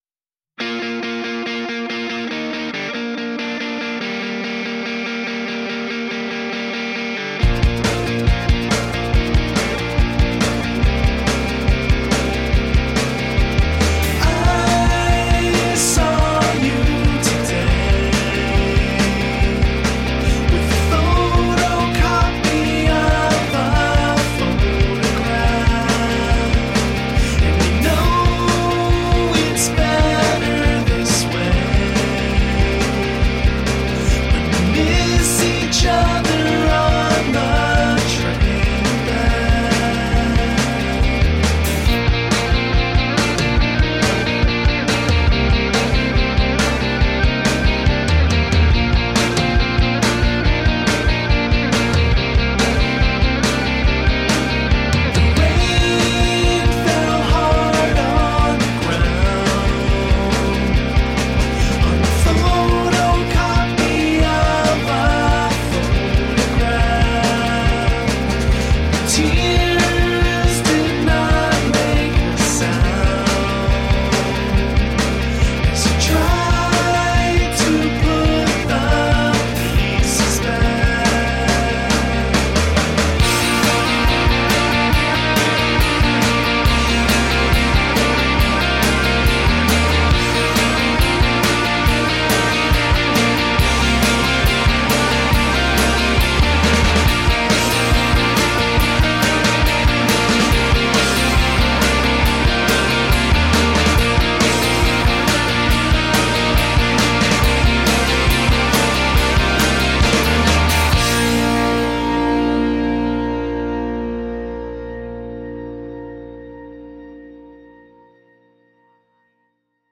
Sophisticated rock with emo undercurrents.